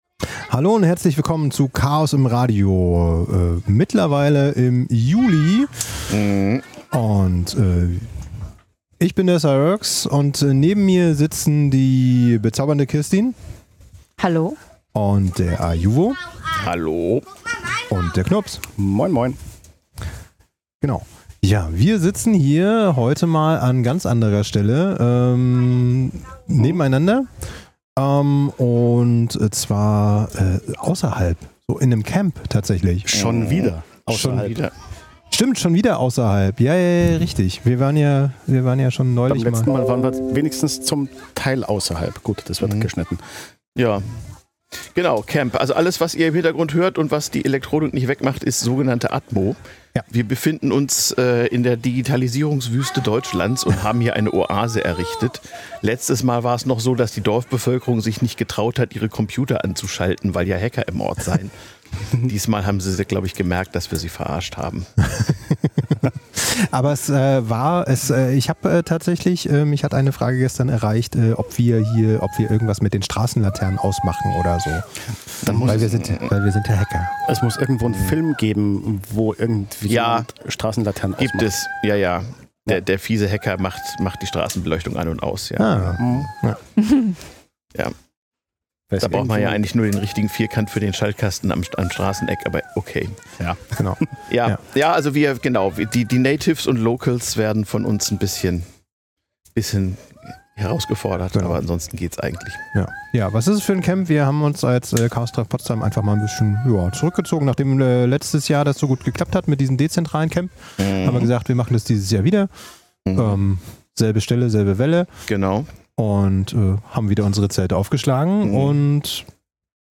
senden vom CCCP-Camp irgendwo aus der digitalen Wüste Brandenburgs...